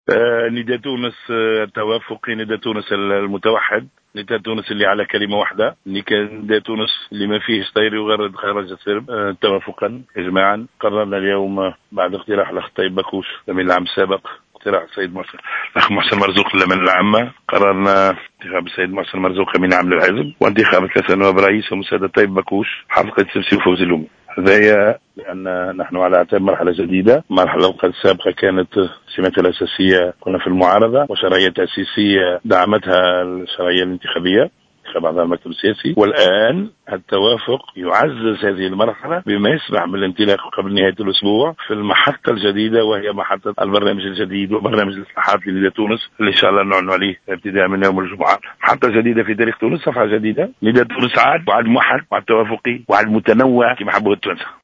Le conseiller politique du président de la République, Mohsen Marzouk, a été officiellement désigné nouveau secrétaire général de Nidaa Tounes, a déclaré mercredi au micro de Jawhara FM Mondher Belhaj Ali.